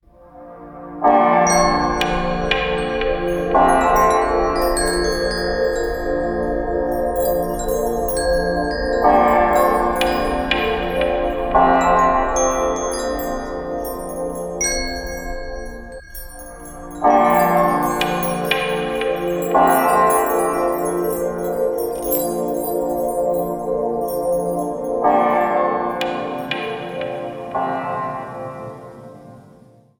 ちなみに、今回僕が入力したテキスト『Woods,mysterious nature music』では以下のような音楽が生成されました。
イメージした音楽とは少し違いましたが、確かに神秘的な音楽が生成されています。
Woodsmysterious-nature-music.mp3